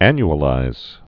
(ăny-ə-līz)